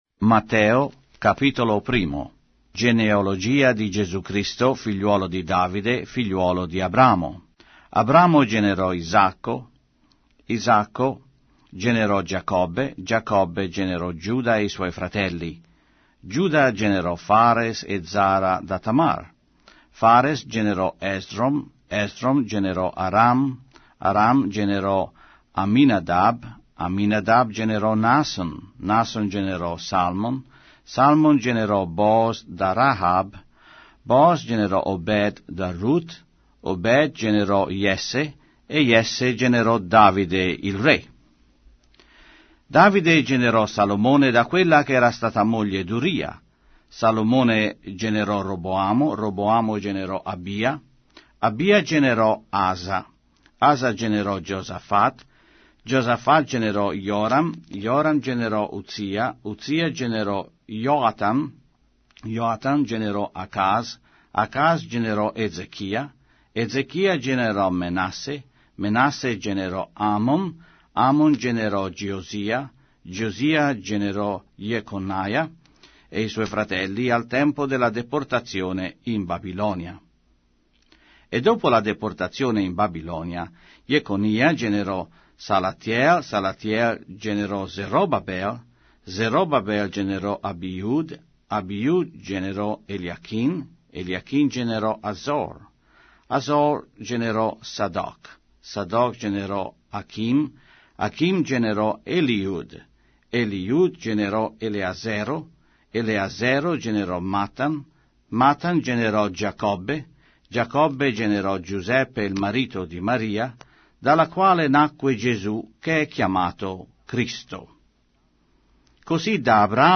Sacra Bibbia - Riveduta - con narrazione audio - Matthew, chapter 1